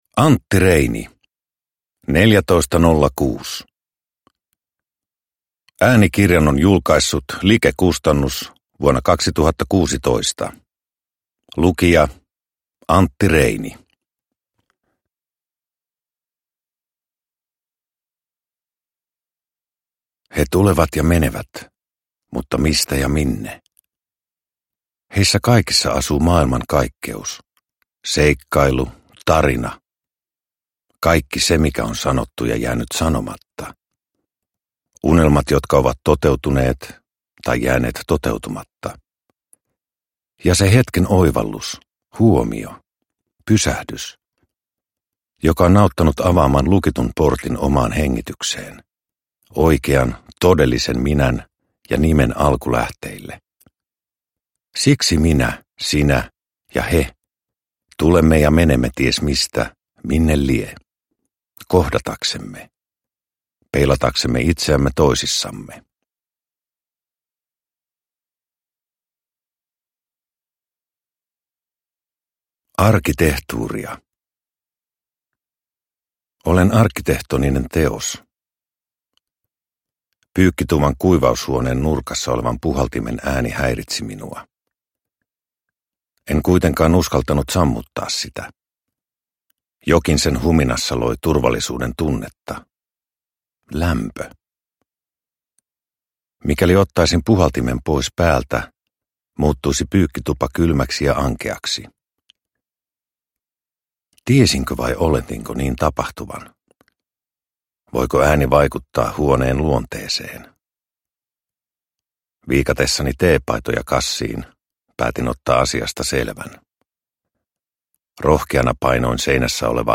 Neljätoistanollakuus – Ljudbok – Laddas ner
Uppläsare: Antti Reini